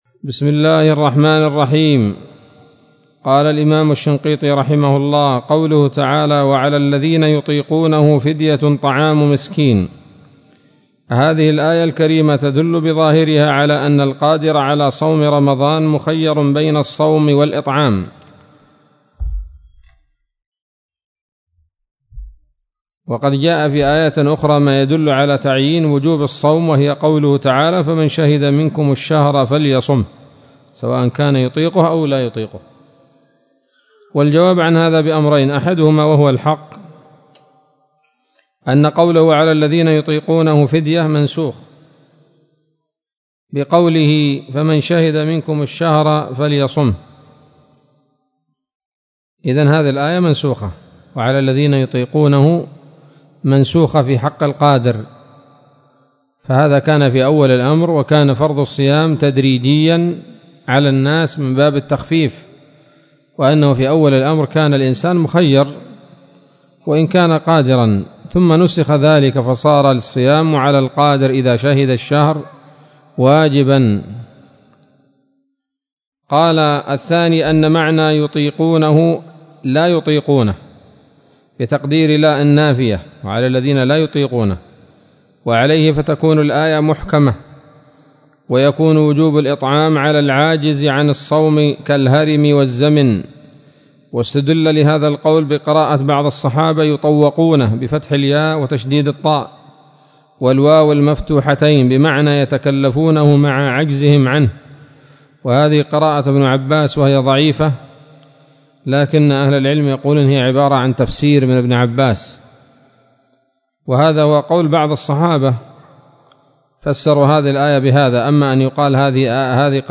الدرس السابع عشر من دفع إيهام الاضطراب عن آيات الكتاب